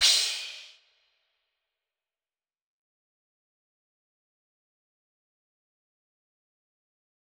DMV3_Crash 9.wav